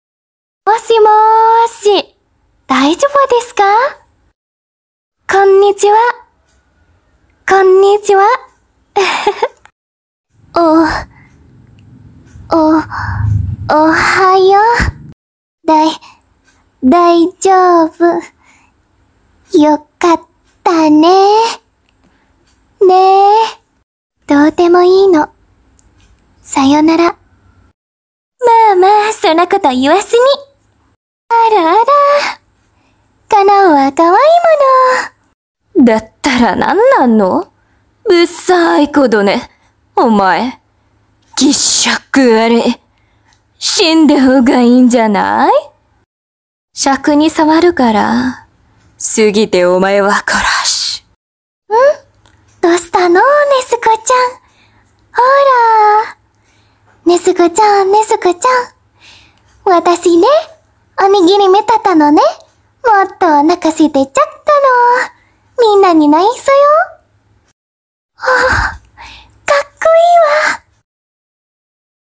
Impersonate anime girls in Kimetsu